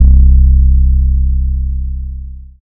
Metro Bass [808].wav